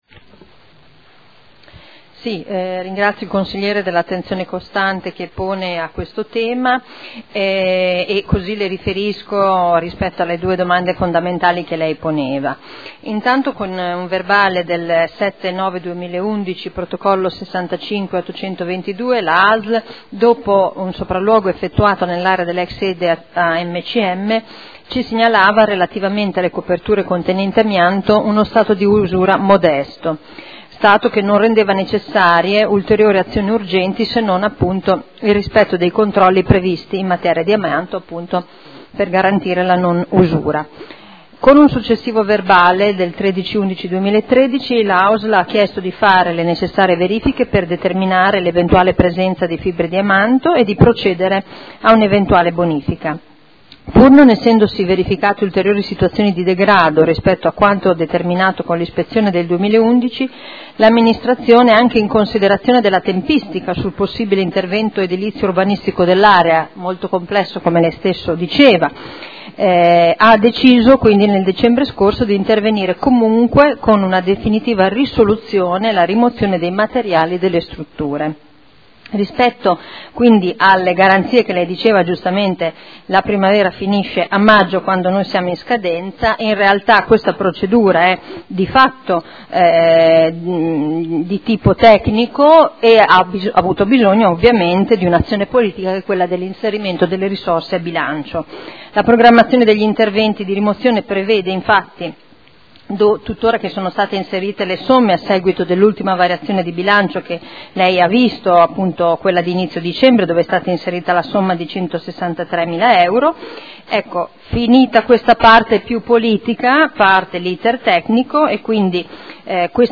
Simona Arletti — Sito Audio Consiglio Comunale